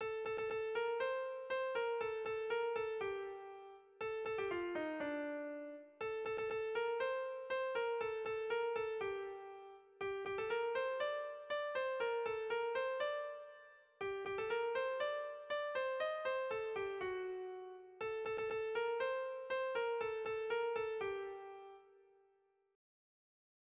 Tragikoa
Hamarreko txikia (hg) / Bost puntuko txikia (ip)
A-A-B1-B2-